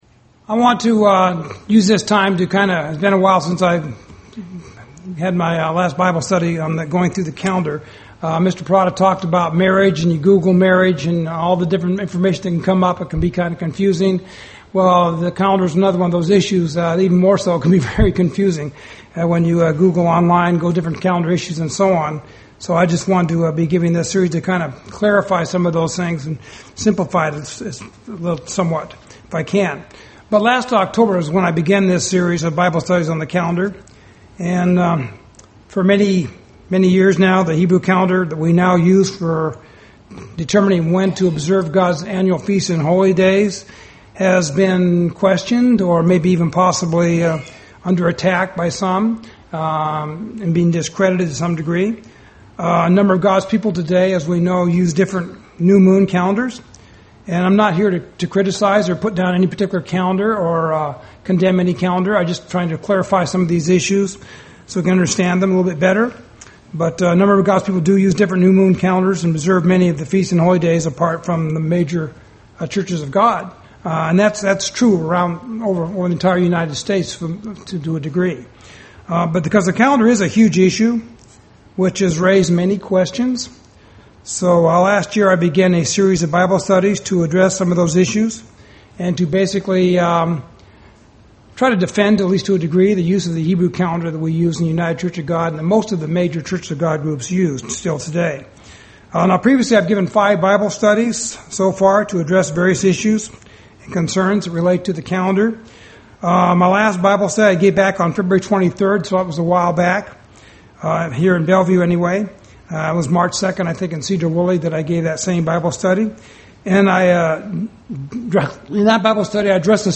Bible Study on the Calendar Part 6